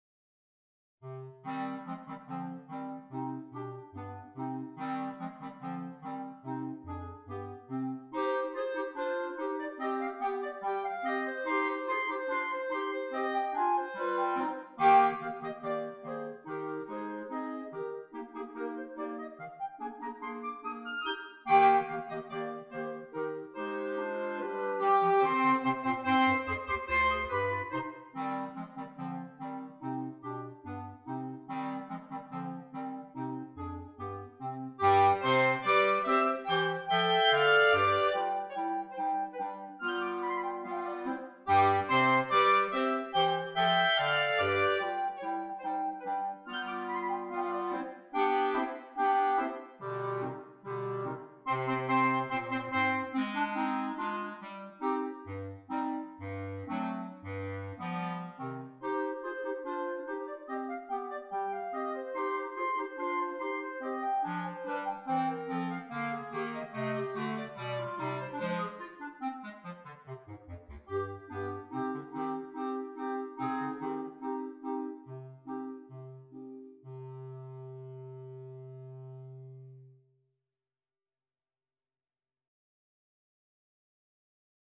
Soprano Clarinet, 3 Clarinets, Bass Clarinet